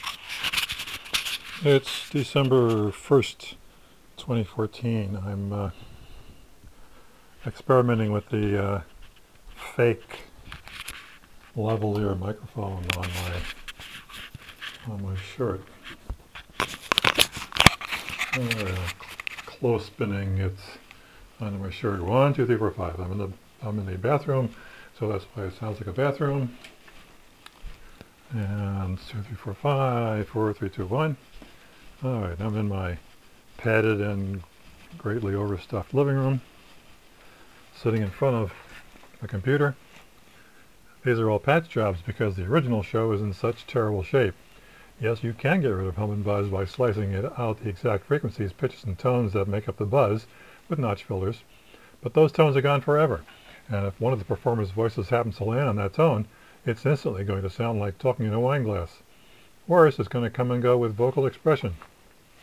Here’s a sound test and picture of the USB microphone in lavalier configuration by way of a wooden clothespin. I need to resolve the rustling handling noises.
All I did was turn it on, wait for the blue light, jam it onto my shirt and start talking.
So at the instant of rustle noise, the Noise Removal stops working and makes it sound like you’re dragging the microphone along the floor with a rope.